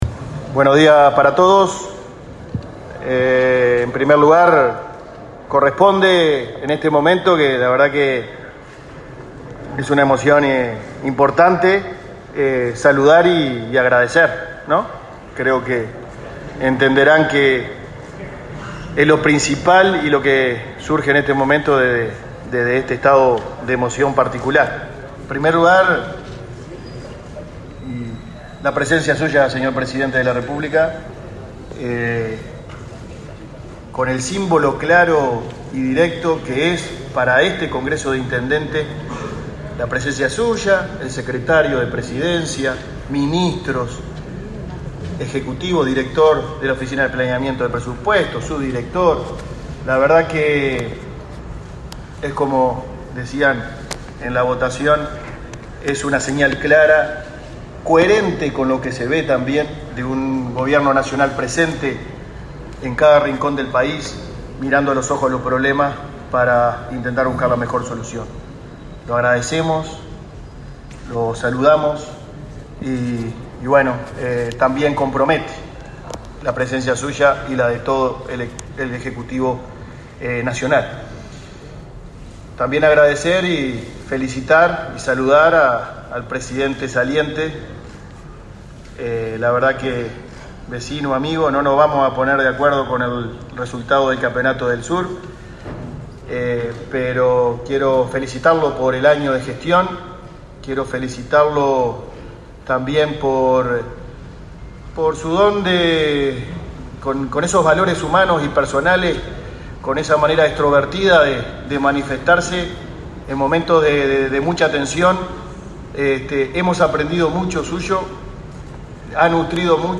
Palabras del presidente del Congreso de Intendentes, Guillermo López
El intendente de Florida, Guillermo López, asumió este miércoles 15 la presidencia del Congreso de Intendentes por el período 2021–2022.